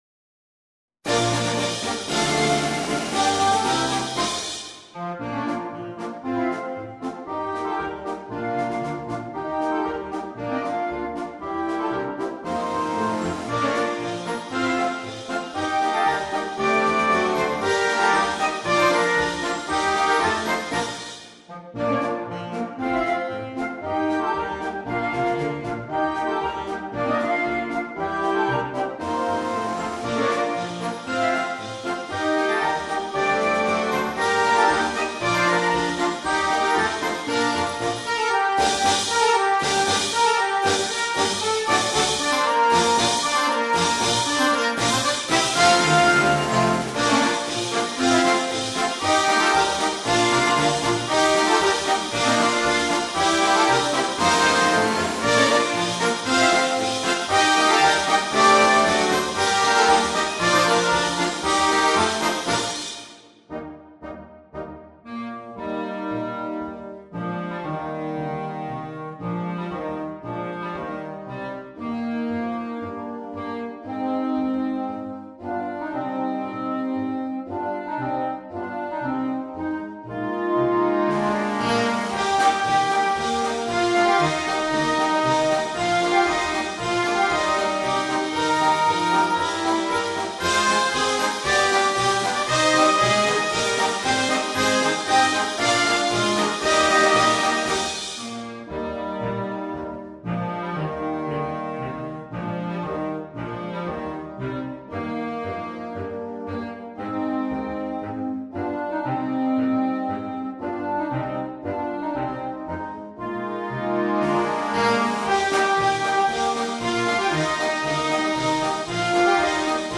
Per banda
Marcia scaricabile anche gratuitamente